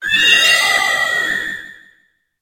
Cri de Sorcilence dans Pokémon HOME.